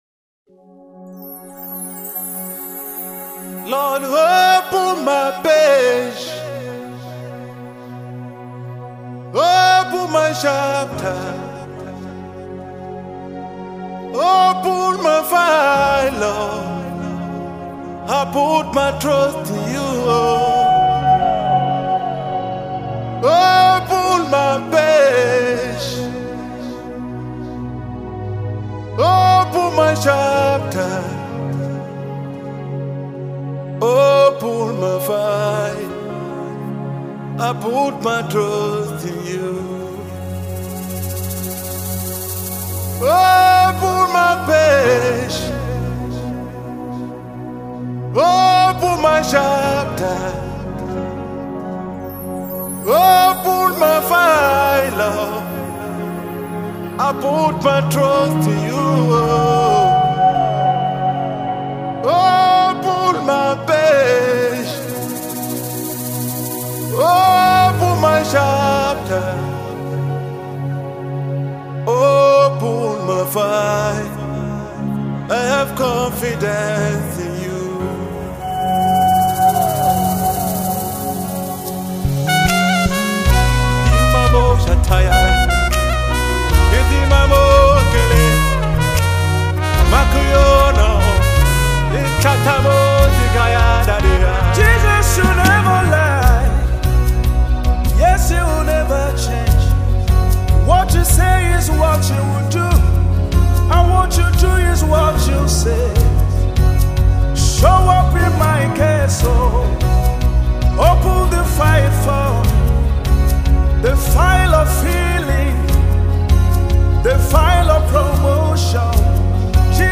a song of prayer and supplication to God almighty